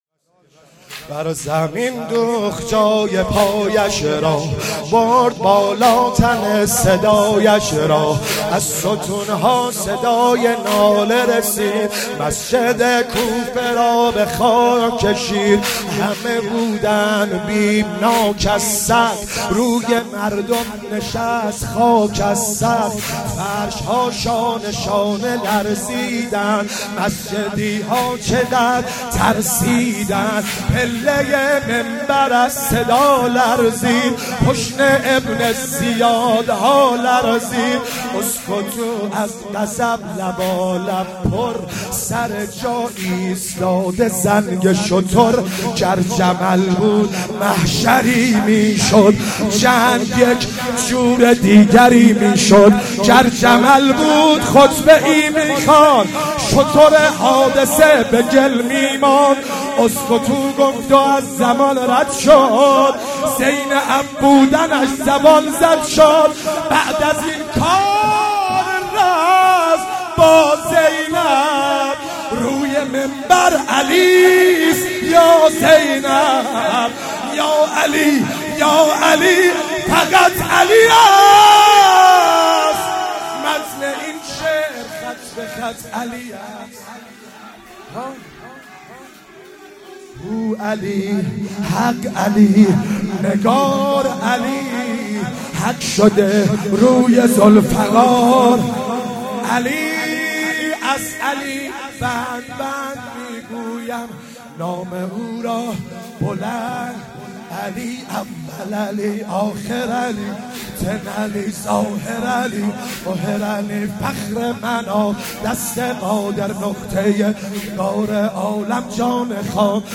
واحد مداحی